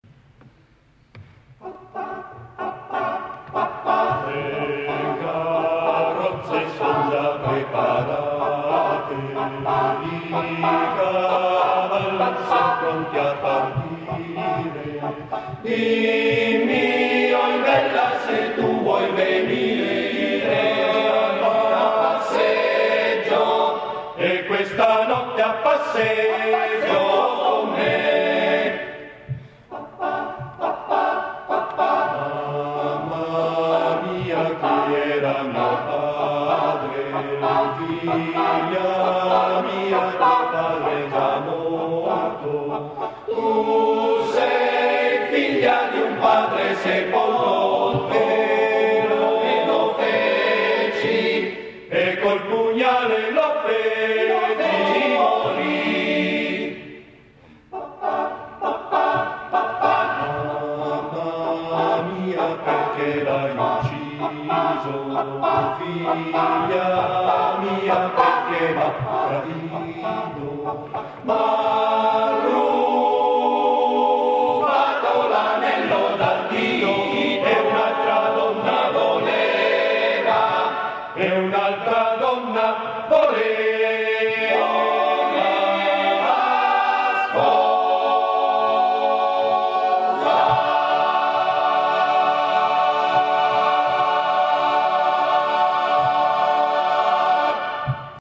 Esecutore: Coro CAI Uget
Fa parte di: Concerto Conservatorio 150° CAI / Coro CAI-UGET